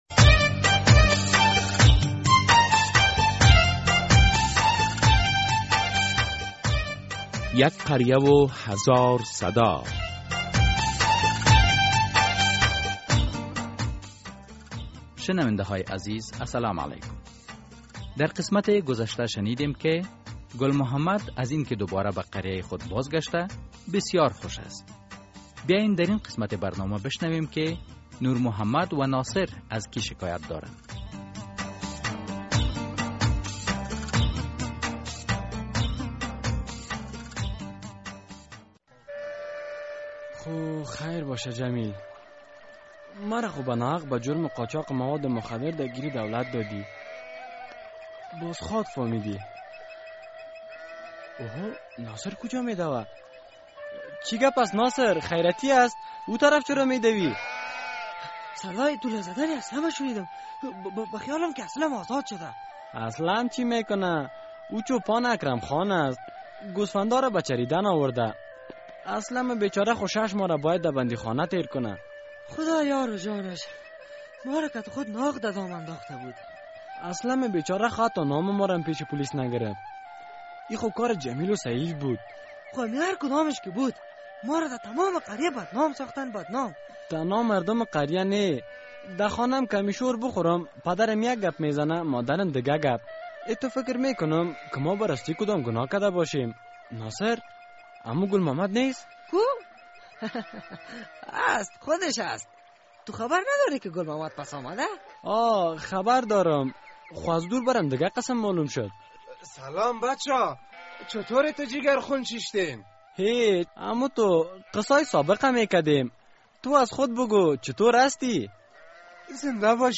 در این درامه که موضوعات مختلف مدنی، دینی، اخلاقی، اجتماعی و حقوقی بیان می‌گردد...